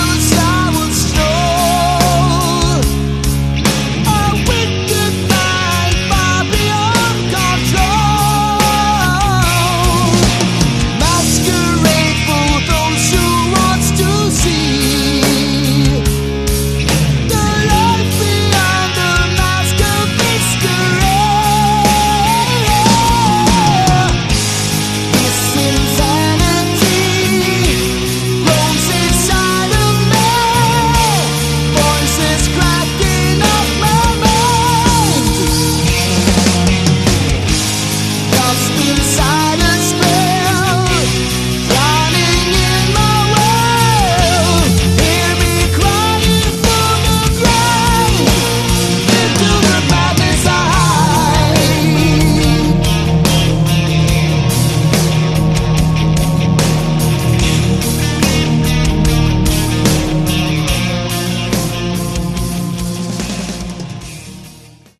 Category: Hard Rock
vocals
guitars
bass
drums
keyboards